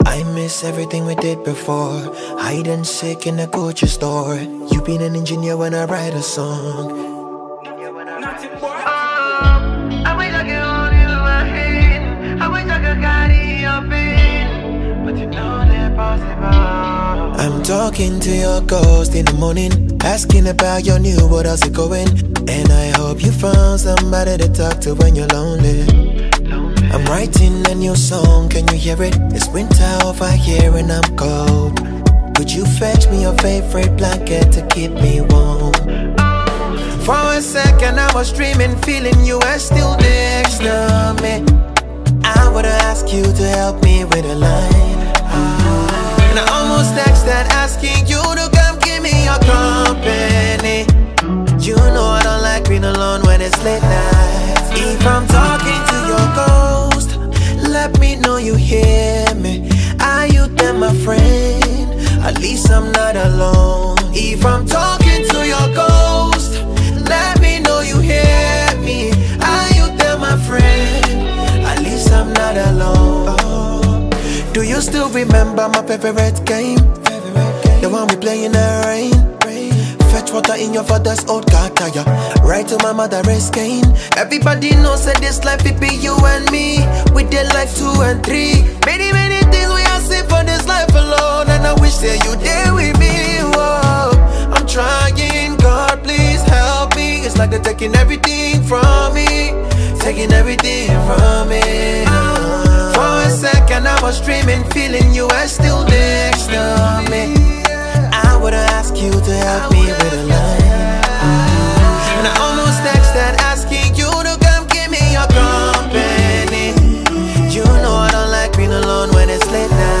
All-time high-rated Afrosound singer